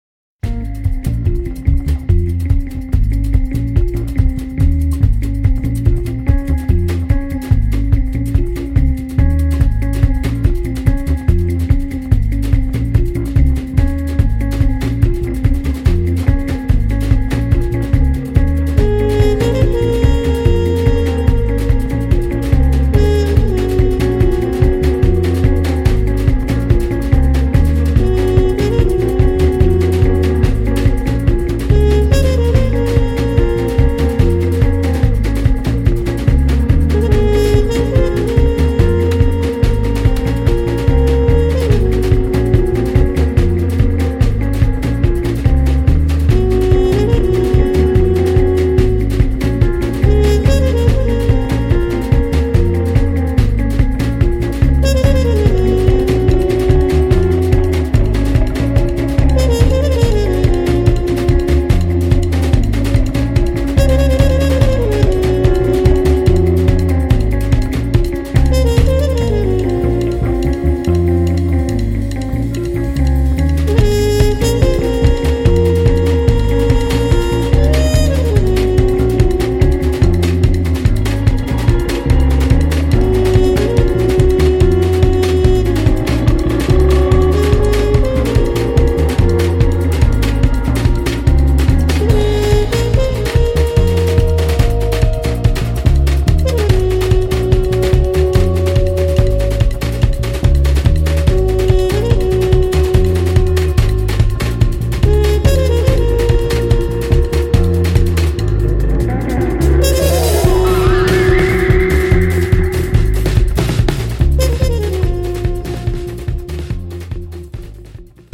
Jazz Music and More